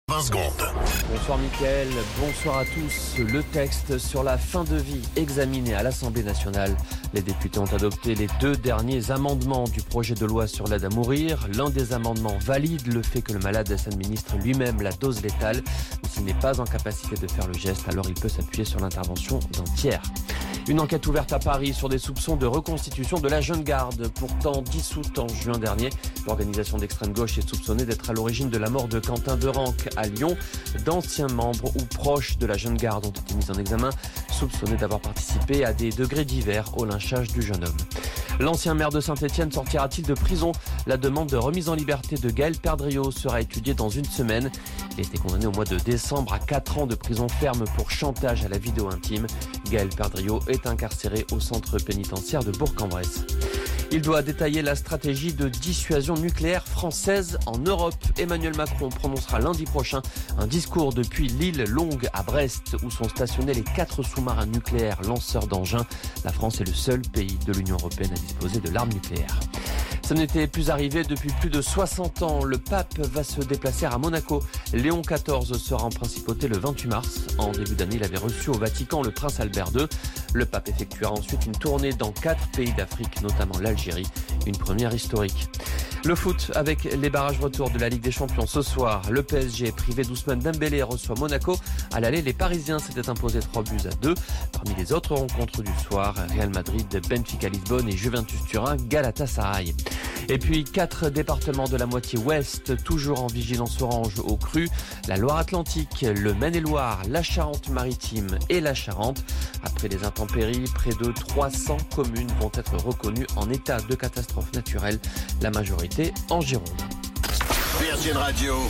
Flash Info National 25 Février 2026 Du 25/02/2026 à 17h10 .